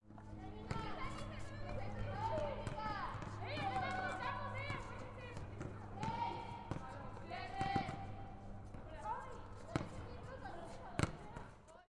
Tag: 篮球 比赛 体育